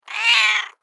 دانلود صدای میو میو گربه گرسنه از ساعد نیوز با لینک مستقیم و کیفیت بالا
جلوه های صوتی